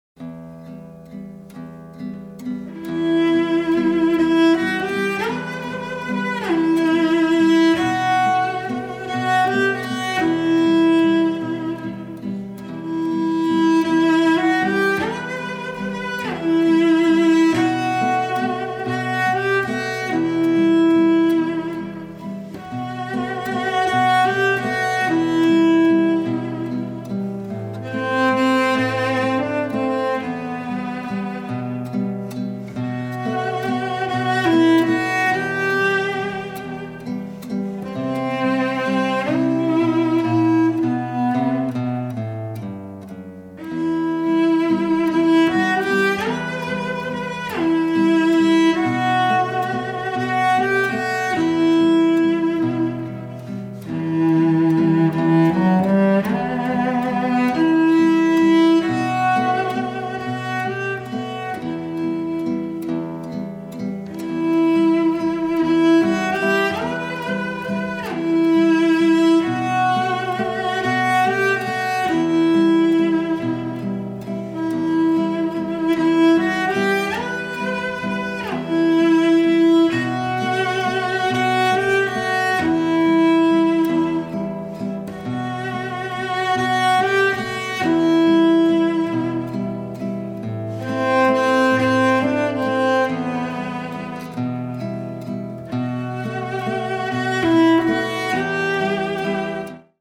★ 吉他與大提琴的完美珍稀組合，展現悠揚樂韻！
★ 豐富飽滿的共鳴、清脆透明的絕佳音響效果！